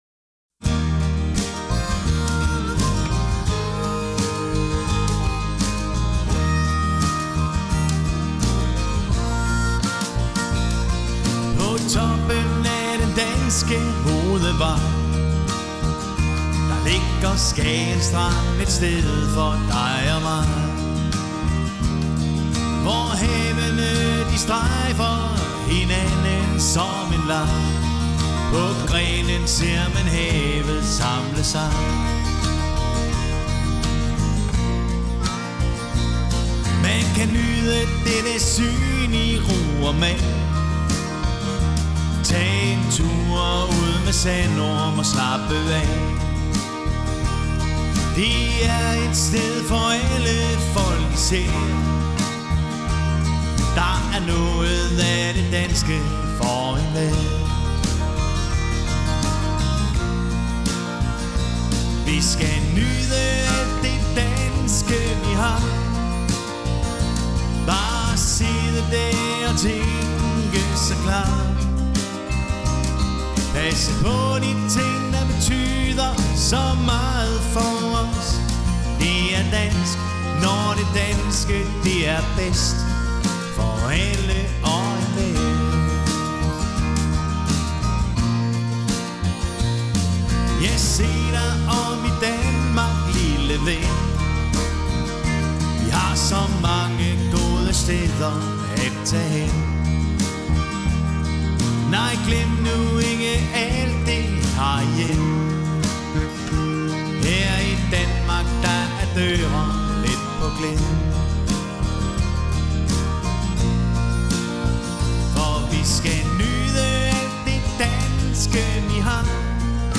Er en duo ,Vi spille til stort set til alle typer af fester.
• Dansk top musik